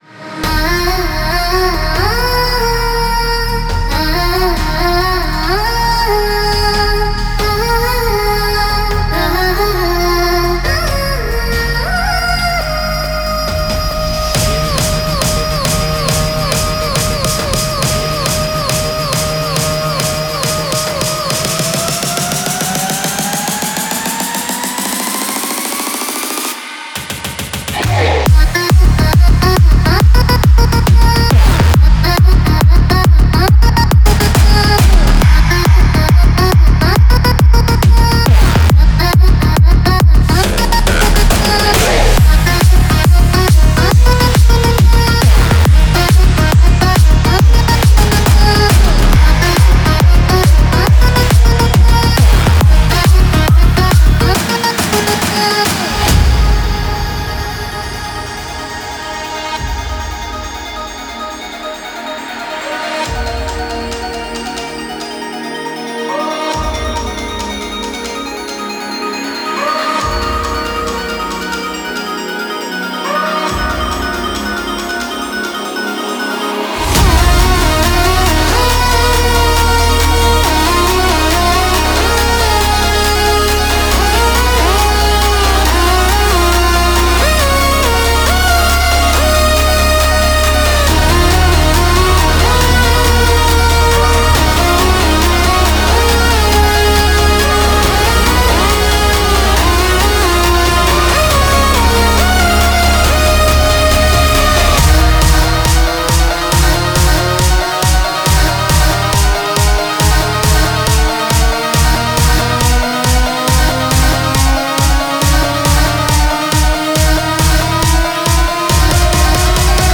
Бодрая музыка для тренировки